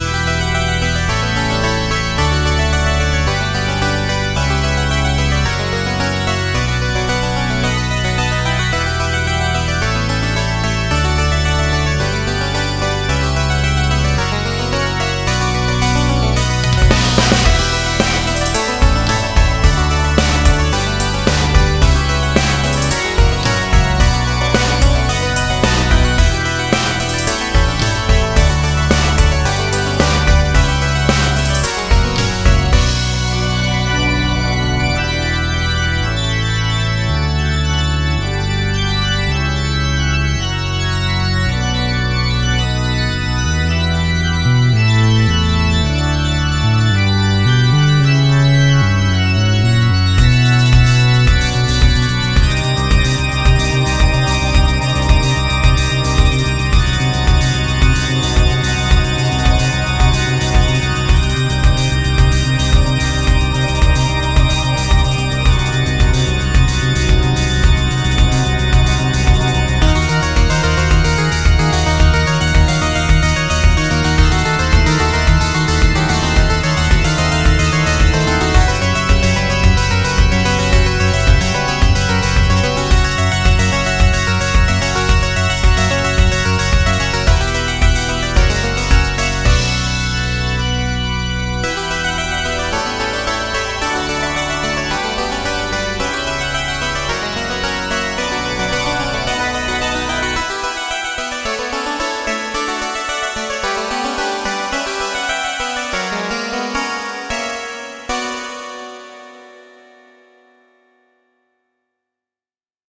BPM35-110
Audio QualityPerfect (High Quality)